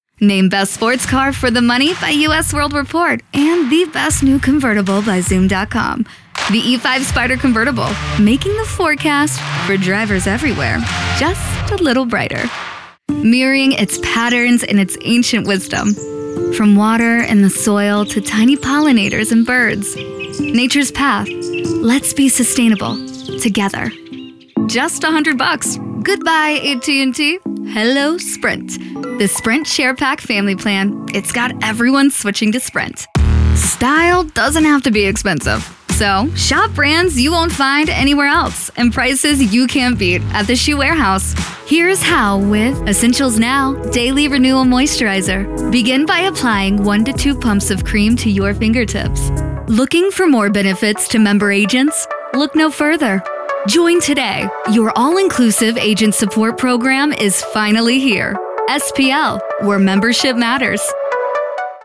F2-Commercial-Demo.mp3